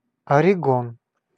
Ääntäminen
Ääntäminen US US : IPA : /ˈɔɹɪɡən/ IPA : /ˈɔɹəɡɪn/ IPA : /ˈɔɹɪɡɒn/ Haettu sana löytyi näillä lähdekielillä: englanti Käännös Ääninäyte Erisnimet 1. Орегон {m} (Oregon) Määritelmät Erisnimet A northwestern state of the United States of America .